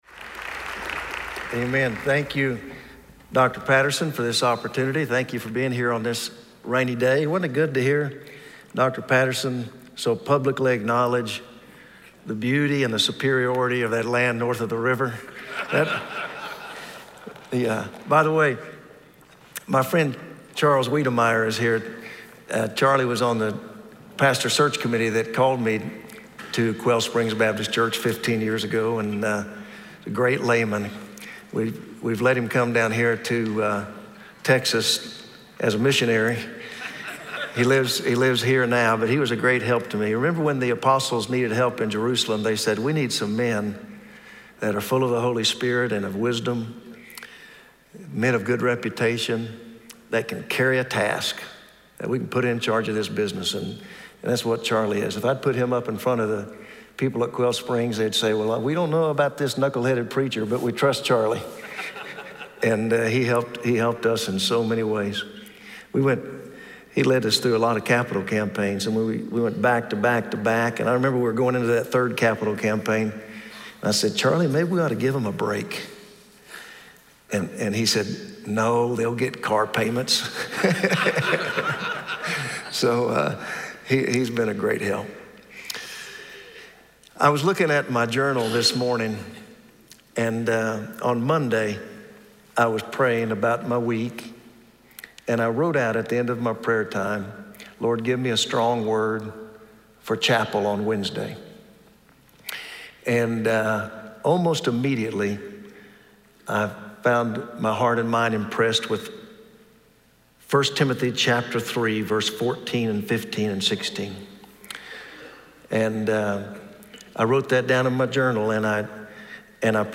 SWBTS Chapel Sermons